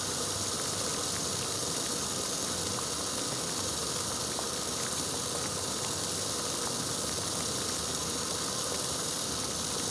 boilding_water_gas_01_loop.wav